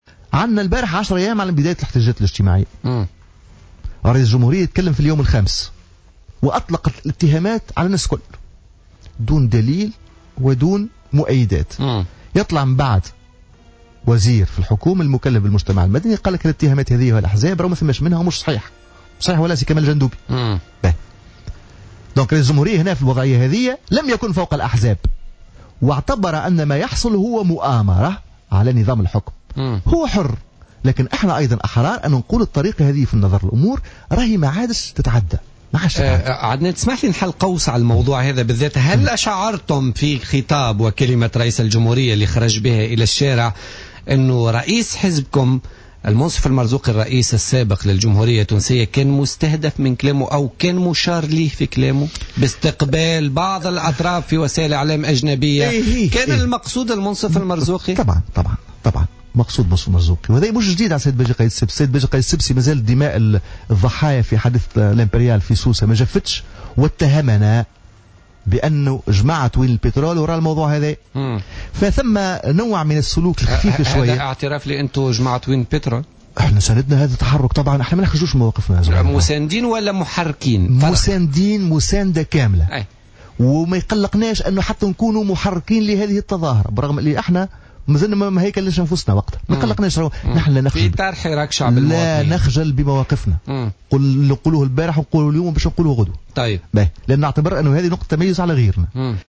انتقد الأمين العام لحزب حراك تونس الإرادة،عدنان منصر ضيف برنامج "بوليتيكا" اليوم الثلاثاء خطاب رئيس الجمهورية الباجي قائد السبسي الأخير والذي اتهم فيه أحزاب سياسية بوقوفها وراء أعمال الشغب التي اندلعت في عدد من المدن التونسية.